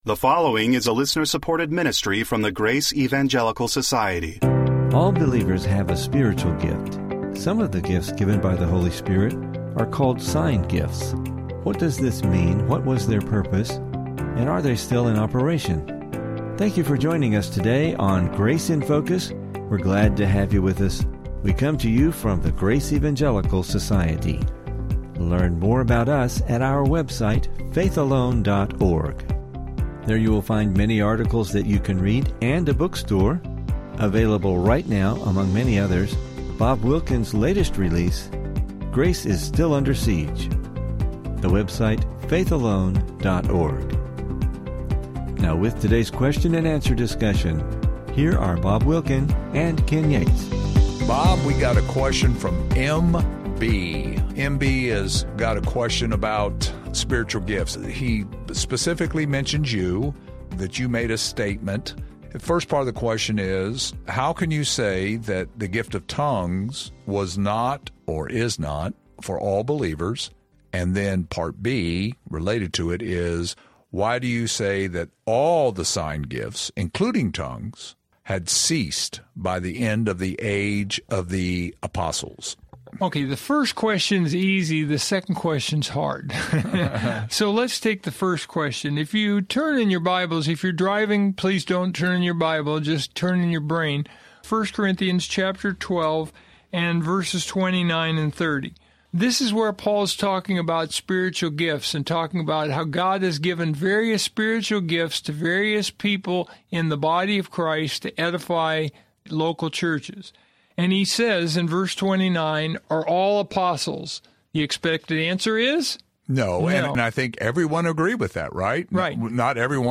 Welcome to Grace in Focus radio.